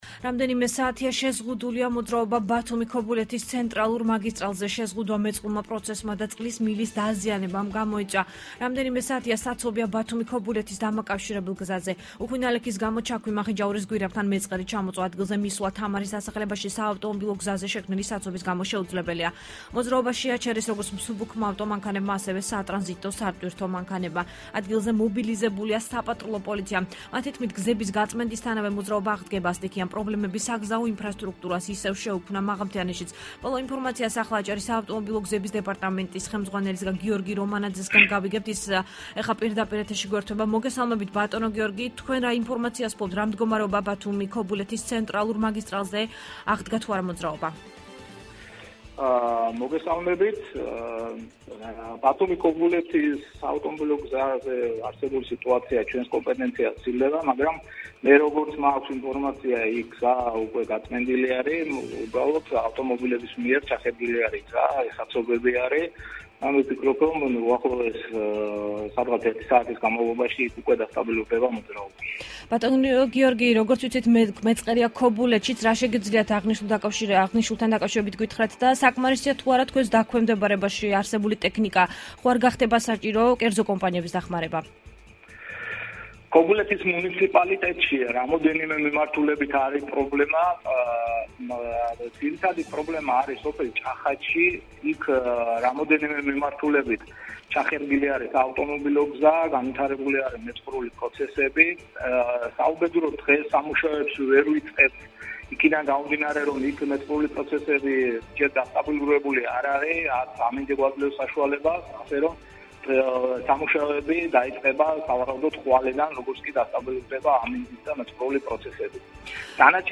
რამდენიმე საათია შეზღუდულია მოძრაობა ბათუმი-ქობულეთის ცენტრალურ მაგისტრალზე. შეზღუდვა მეწყრულმა პროცესმა და წყლის მილის დაზიანებამ გამოიწვია. საცობია ბათუმი-ქობულეთის დამაკავშირებელ გზაზე.  უხვი ნალექის გამო ჩაქვი-მახინჯაურის გვირაბთან მეწყერი ჩამოწვა. ადგილზე მისვლა თამარის დასახლებაში საავტოობილო გზაზე შექმნილი საცობის გამო შეუძლებელია. მოძრაობა შეაჩერეს, როგორც მსუბუქმა ავტომანქანებმა, ასევე სატრანზიტო, სატვირთო მანქანებმა. ადგილზე მობილიზებულია საპატრულო პოლიცია. მათი თქმით, გზების გაწმენდისთანავე მოძრაობა აღდგება. სტიქიამ პრობლემები საგზაო ინფრასტქრუქტურას ისევ შეუქმნა მაღალმთიანეთშიც. ''რადიო აჭარის'' საინფორმაციო სამსახურის პირდაპირ ეთერში აჭარის საავტომობილო გზების დეპარტამენტს ხელმძღვანელისგან გიორგი რომანაძე საუბრობდა.